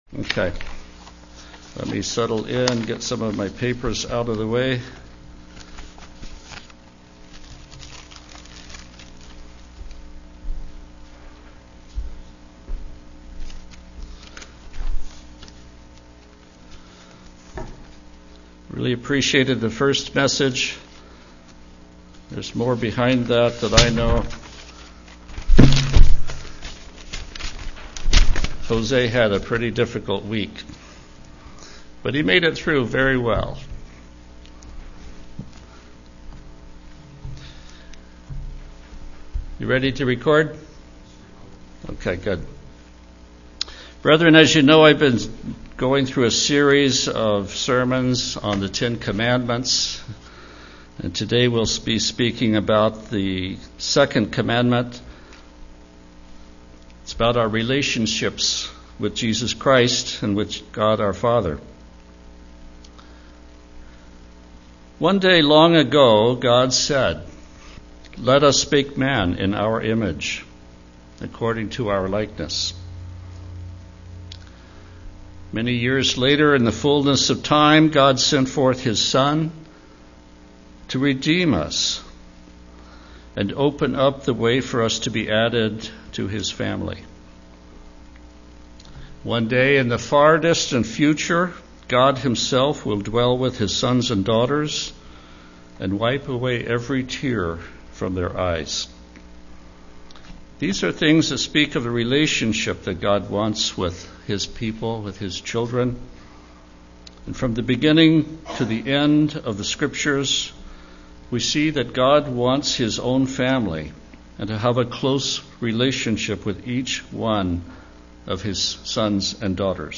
Sermons
Given in Olympia, WA